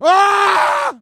scream_short_0.ogg